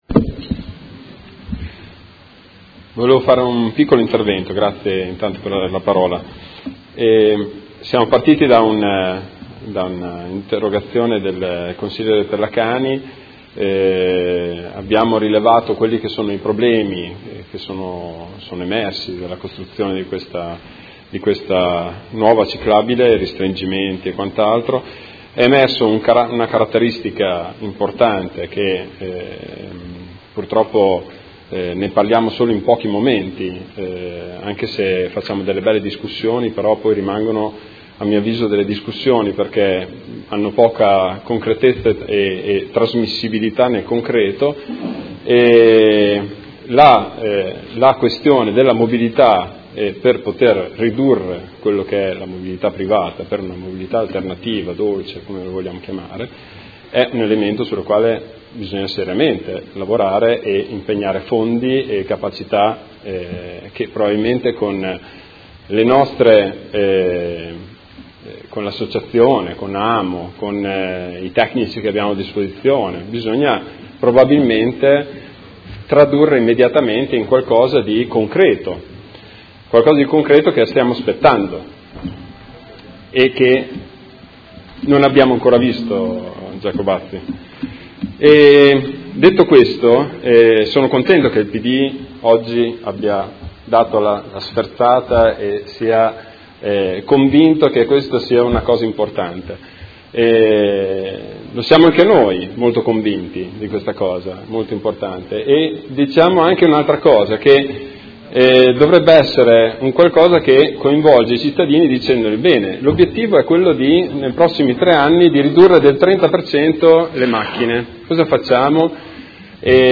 Seduta del 31/03/2016. Interrogazione del Gruppo Consiliare Forza Italia avente per oggetto: Restringimento della carreggiata di Via Pietro Giardini. Dibattito